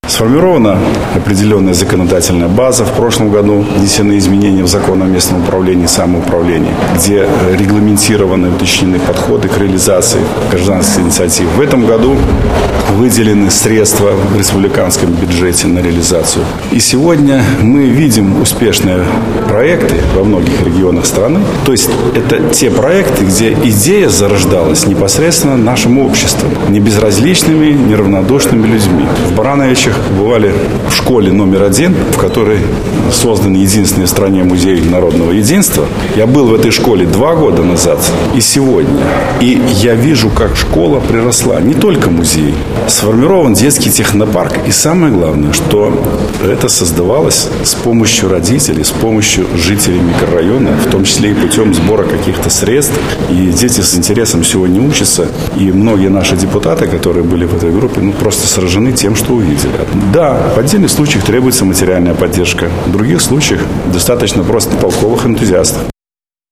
Воплощение в жизнь таких проектов — важное направление работы, — подчеркнул спикер Палаты представителей Игорь Сергеенко.